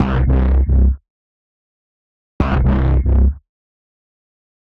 tx_synth_100_grump_C.wav